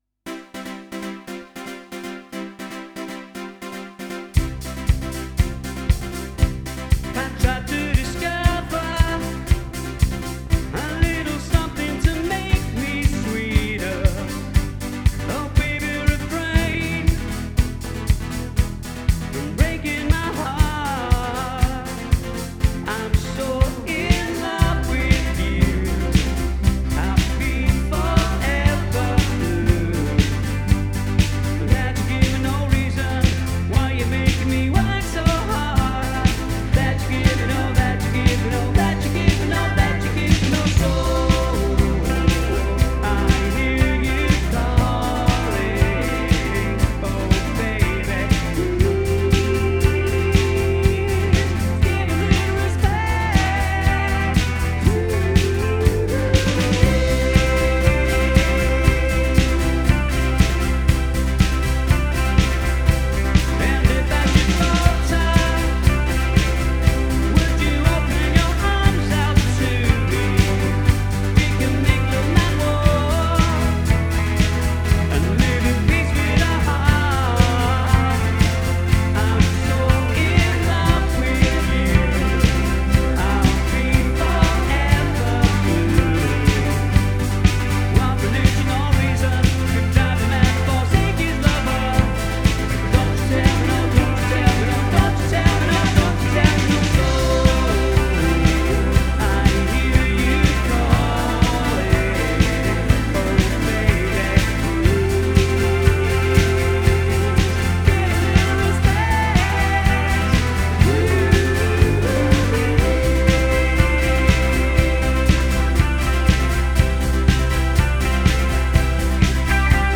All tracks were recorded live